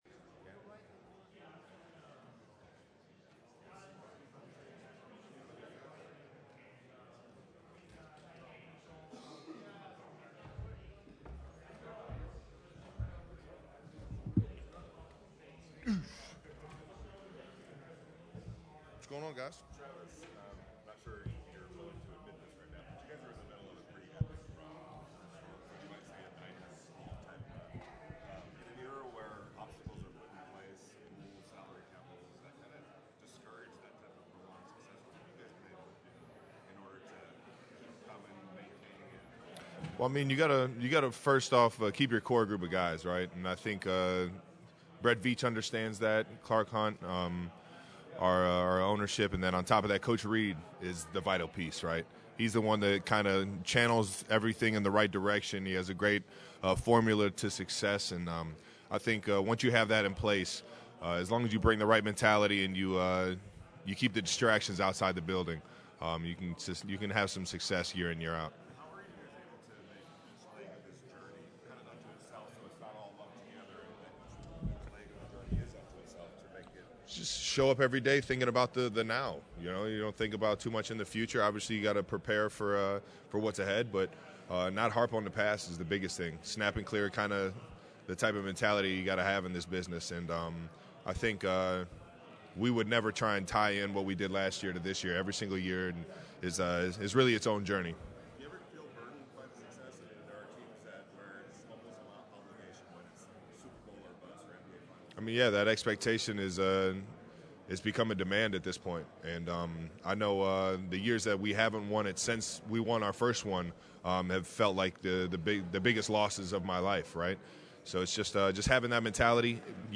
Chiefs pressers from Tuesday